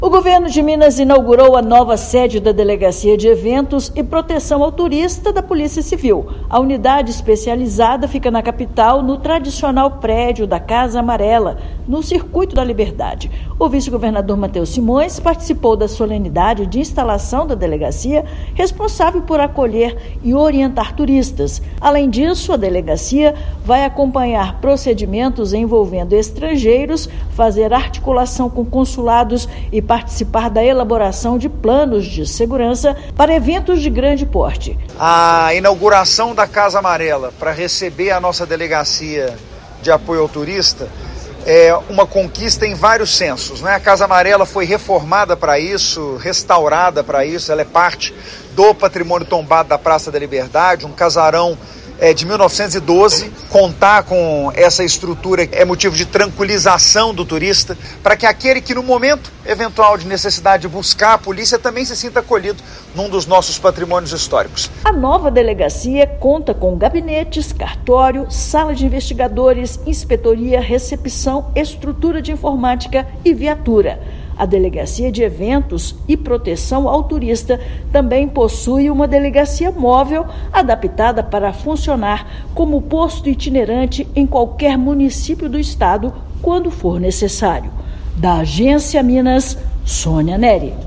Unidade estratégica de atendimento funcionará em prédio histórico tombado do Conjunto Arquitetônico e Paisagístico da Praça da Liberdade. Ouça matéria de rádio.